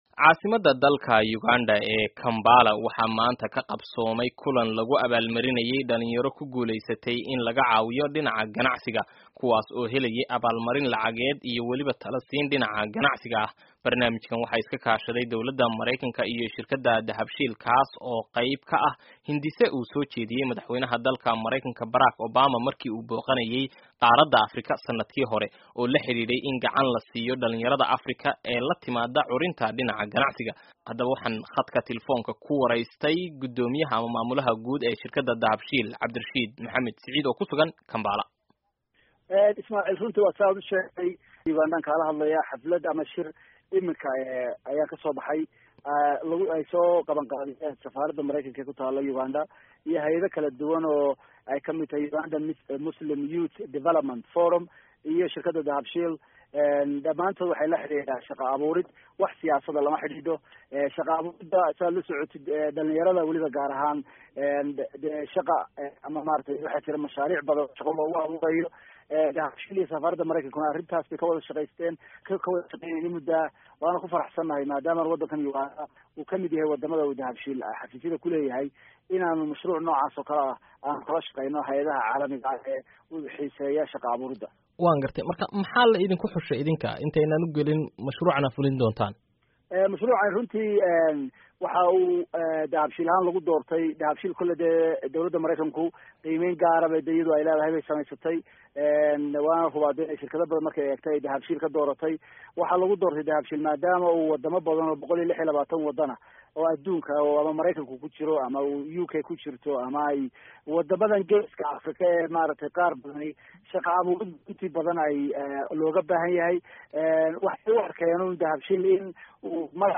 Waraysiga Shirka Kampala